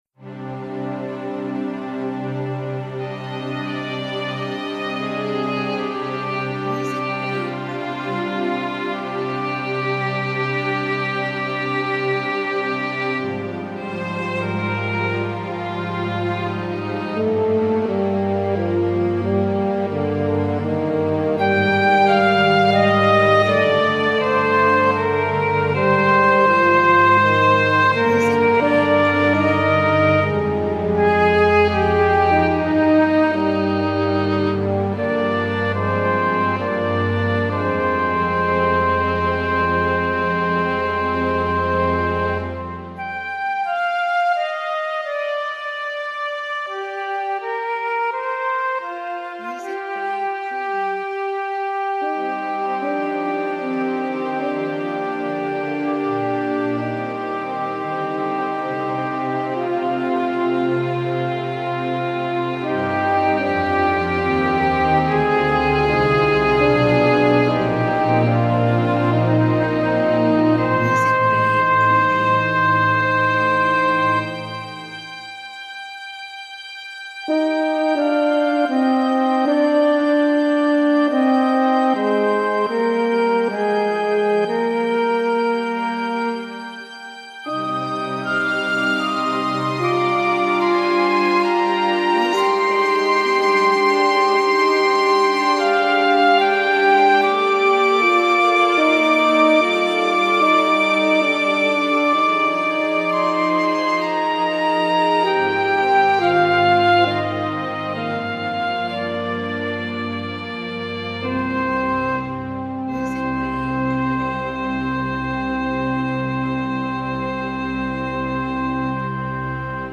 Cinematic music backgrounds